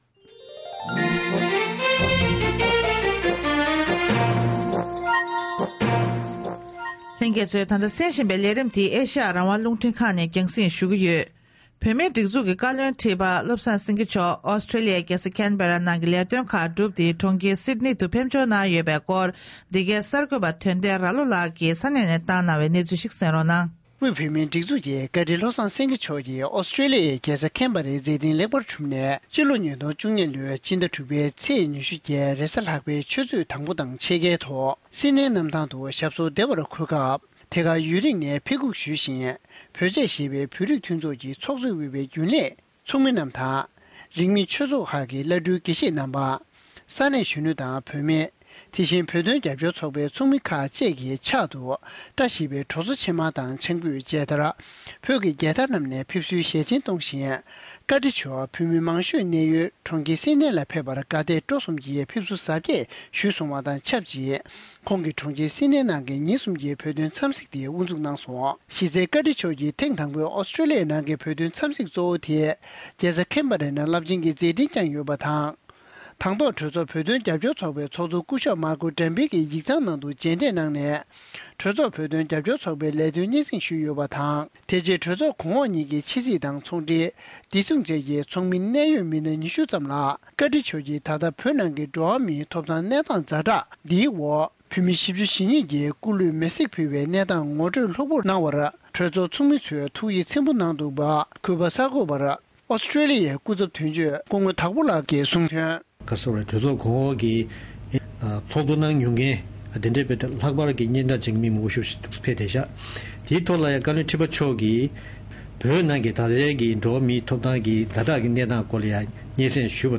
སྒྲ་ལྡན་གསར་འགྱུར།